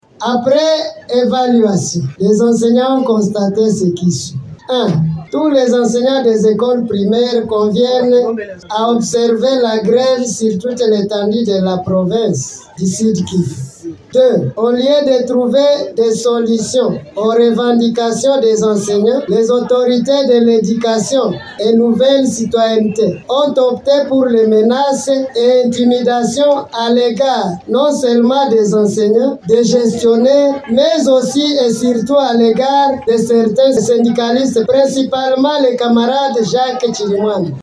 Décision prise dans une assemblée générale extraordinaire tenue par les enseignants à Bukavu jeudi 10octobre 2024.